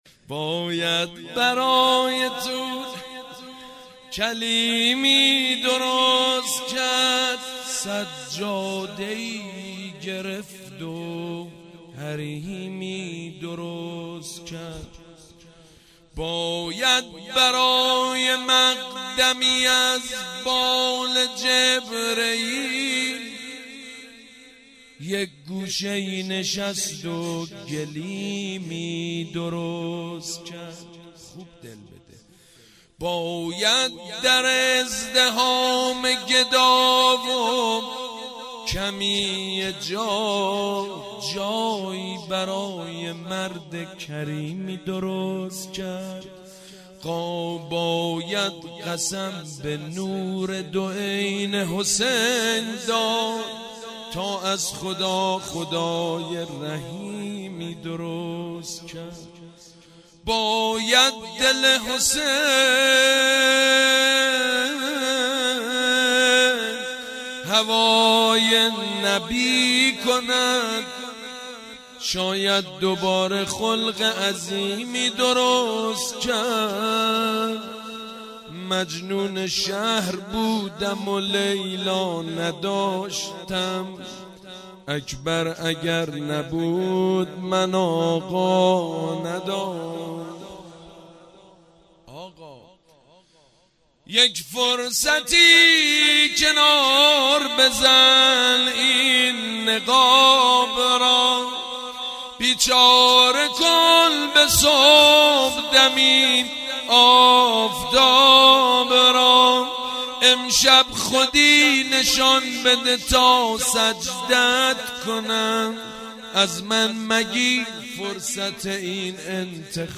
مولودی به مناسبت ولادت حضرت علی اکبر(ع)